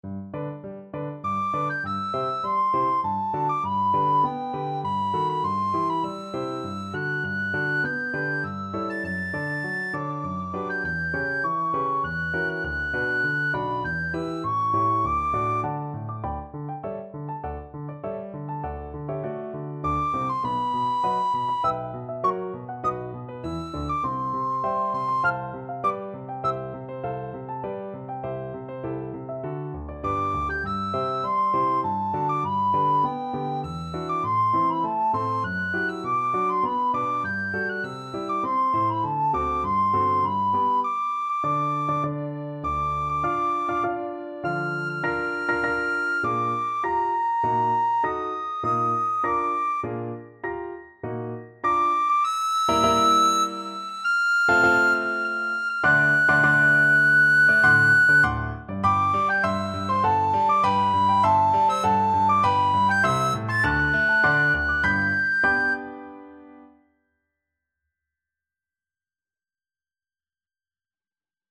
Free Sheet music for Soprano (Descant) Recorder
G major (Sounding Pitch) (View more G major Music for Recorder )
~ = 100 Tempo di Menuetto
3/4 (View more 3/4 Music)
Classical (View more Classical Recorder Music)